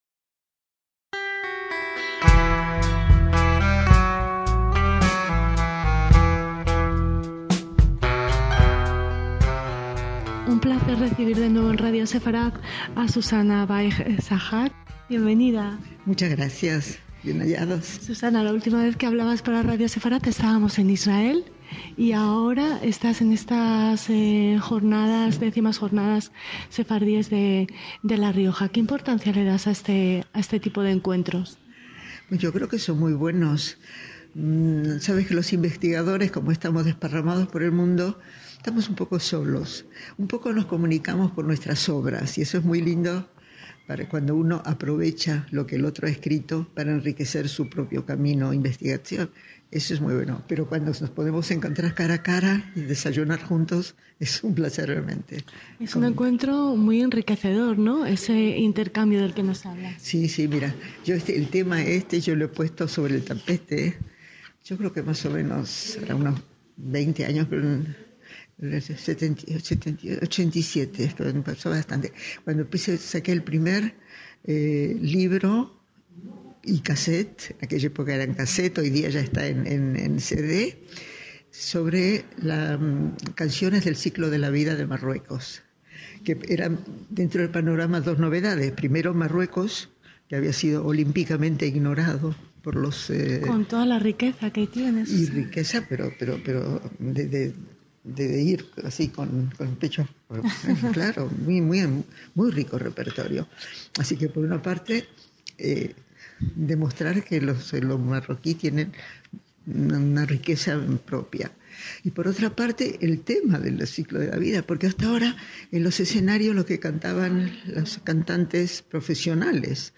DESDE LAS X JORNADAS SEFARDÍES EN LA RIOJA